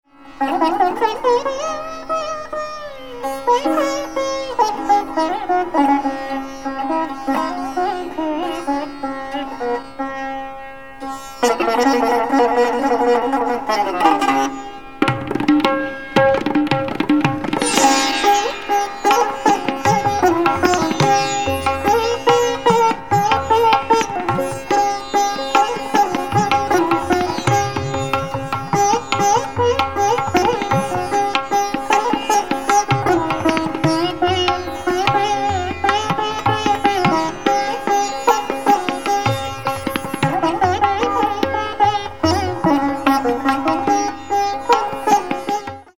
Aroha: nSgmP, gmndnS
Avroh: SnDndP, mgPmgRgrS
Chalan: variable (shuddha Re given greater prominence)